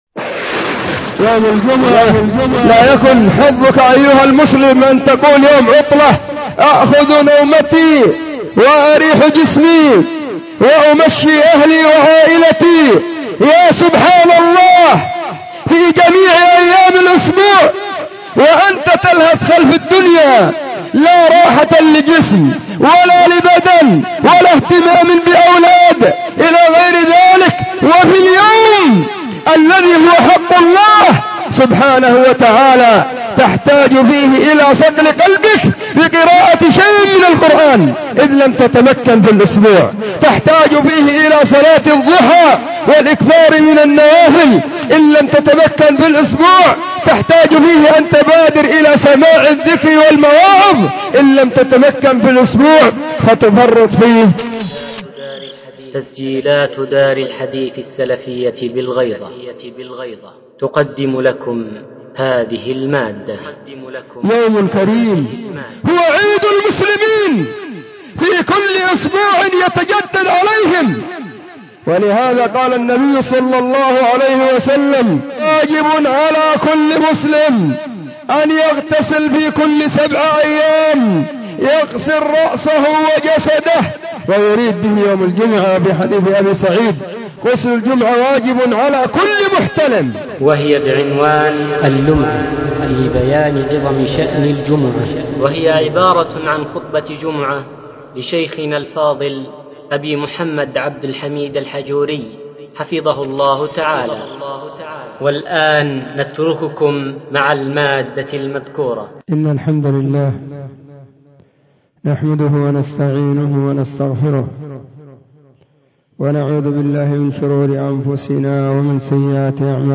خطبة جمعة بعنوان : *🪴اللمعة في بيان عظيم شأن الجمعة🪴*
📢 وكانت – في – مسجد – الصحابة – بالغيضة – محافظة – المهرة – اليمن.